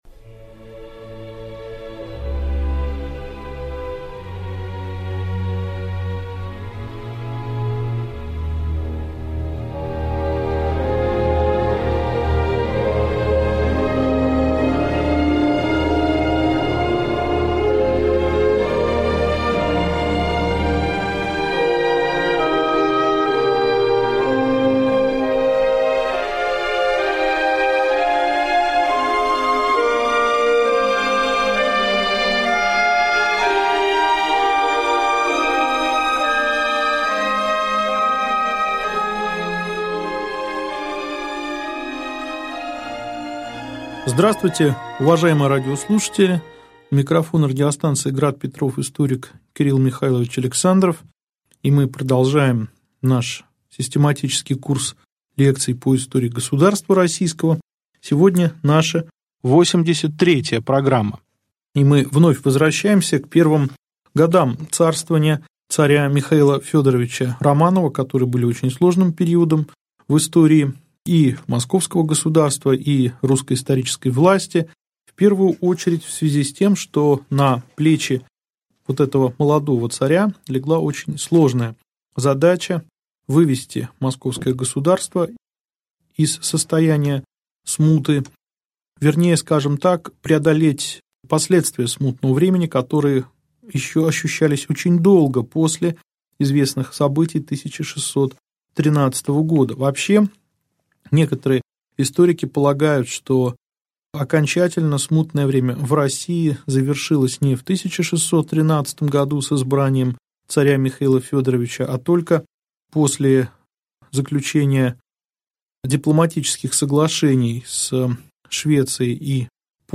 Аудиокнига Лекция 83. Мир с Польшей. Обзор русского общества в первой трети XVII в. | Библиотека аудиокниг